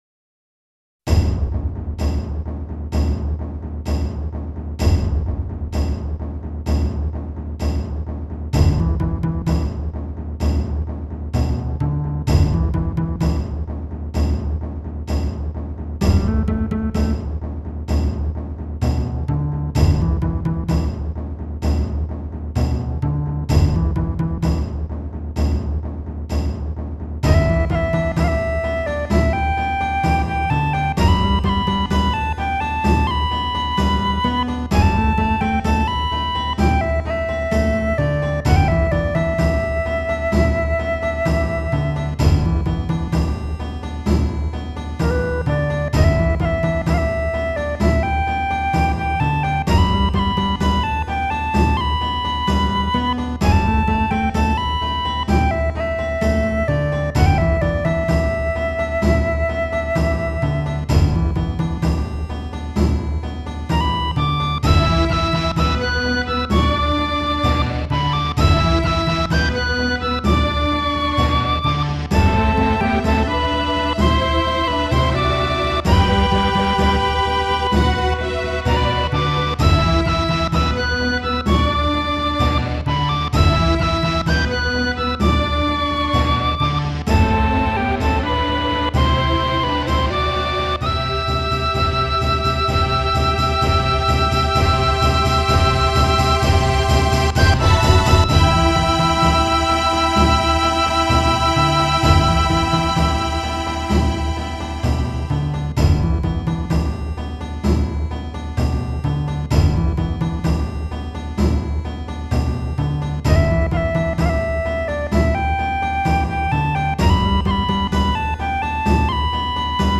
d'allure très rudimentaire et populaire